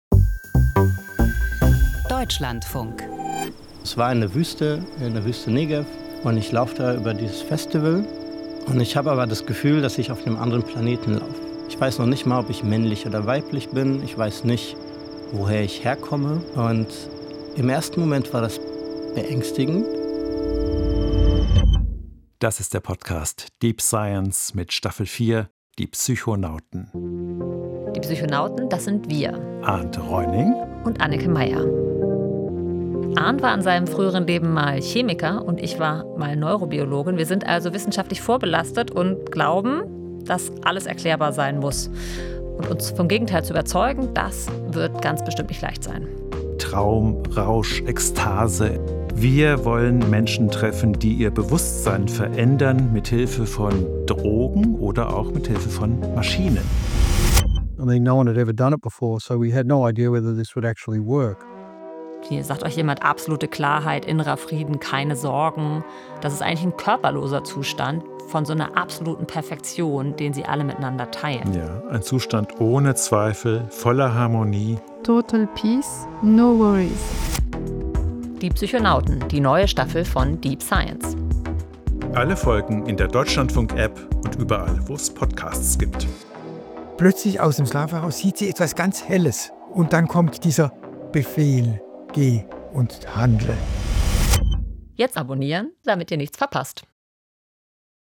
TRAILER